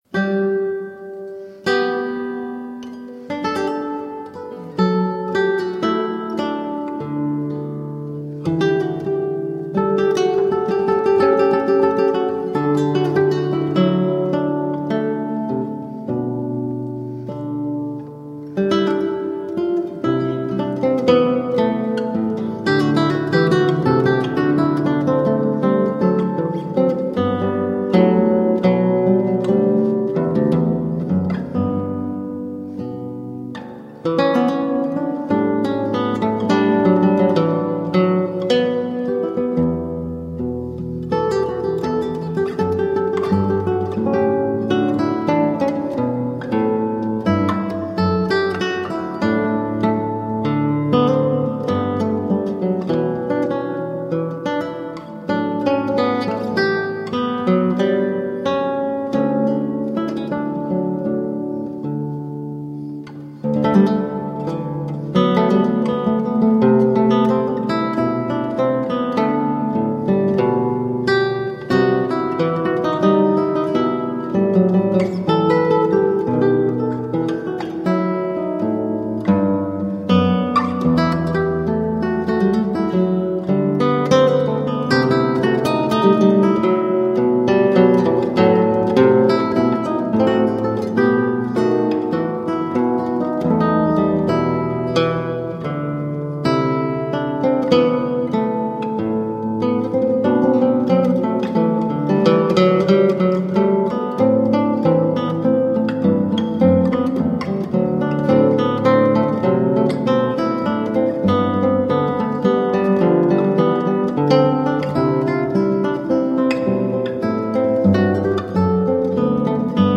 Elegant classical guitar.